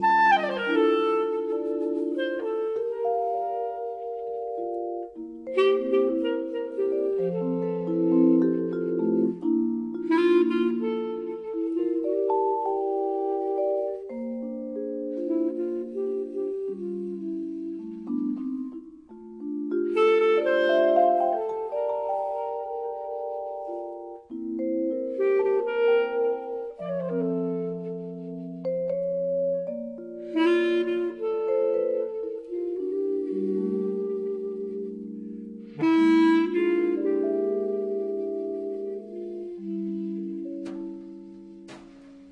The Best In British Jazz
Recorded at Abbey Road Studio One London 2007